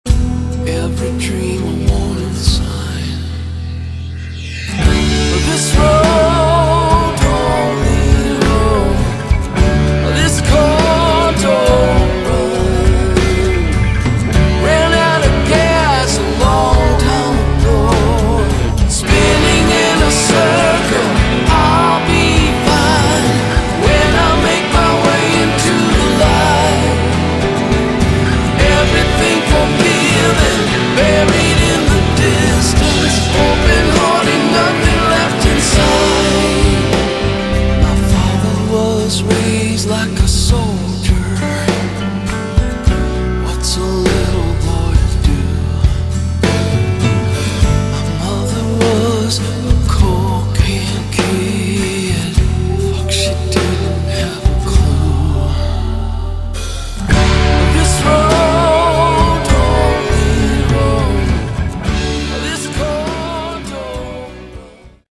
Category: Hard Rock
vocals, guitar
drums